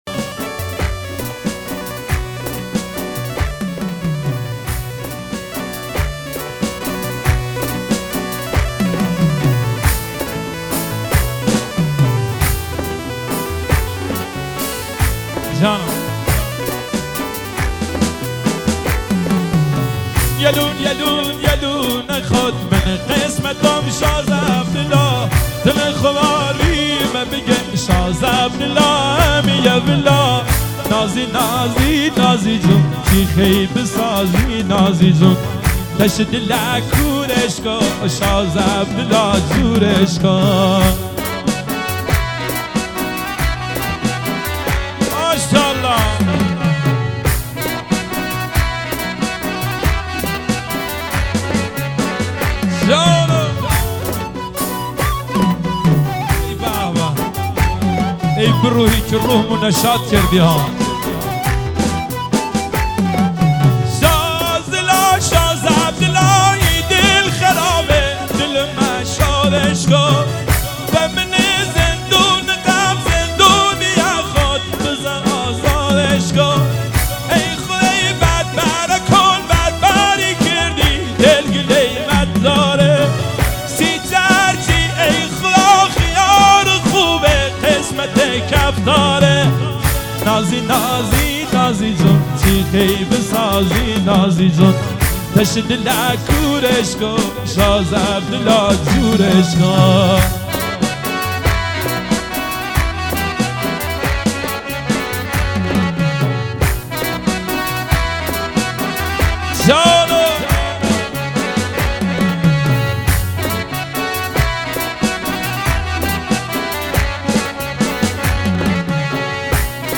ترانه و موزیک شاد و ریتمیک.